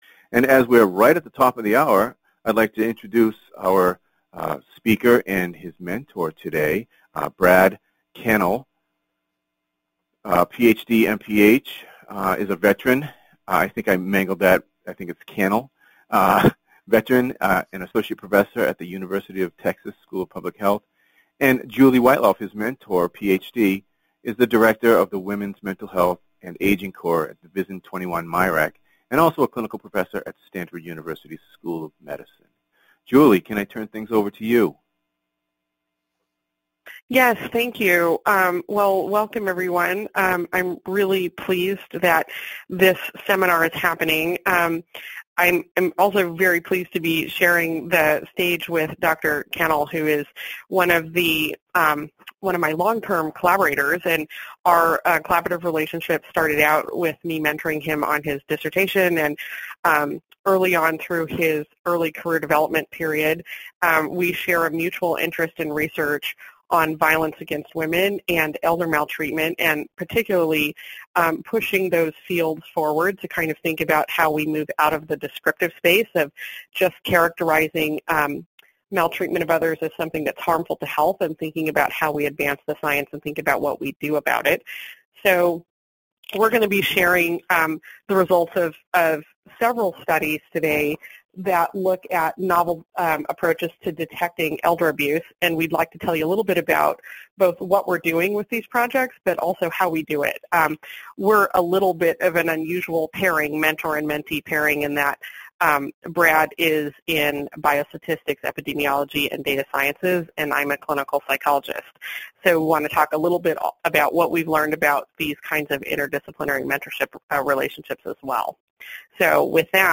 PhD Seminar date